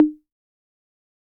Tom
808-Tom-2.wav